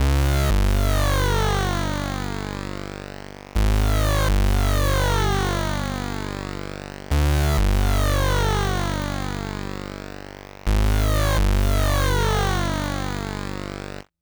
synth02.wav